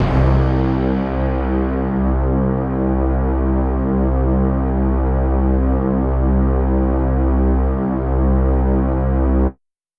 Trumpets Brass Horns.wav